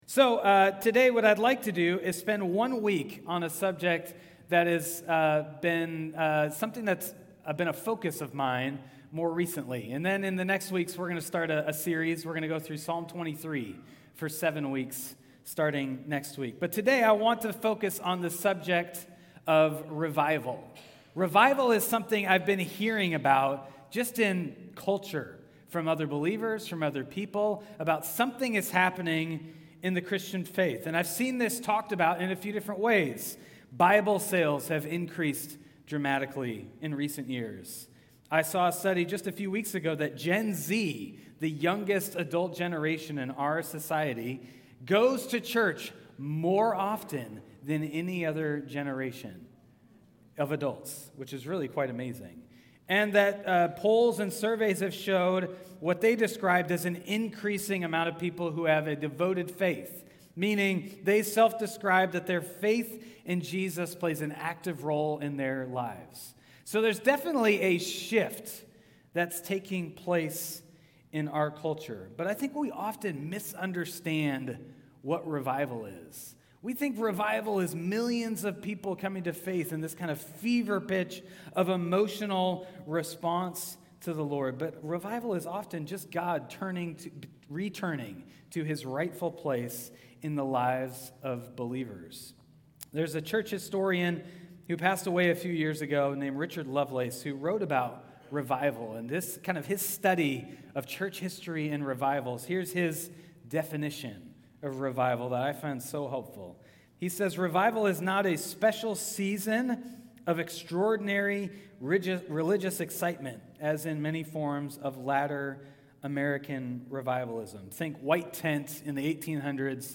New Harvest Church, Salem, Oregon
Acts 3:11-26 (Stand-Alone Sermon)